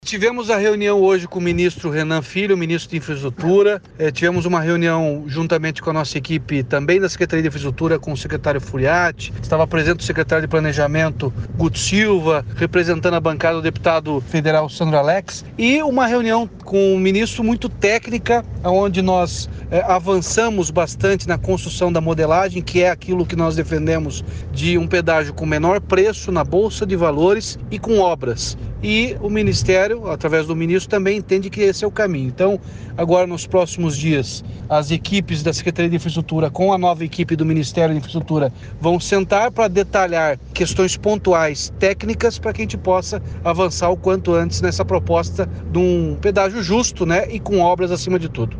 Sonora do governador Ratinho Junior sobre a reunião com o ministro dos Transportes para discutir o novo pedágio do Paraná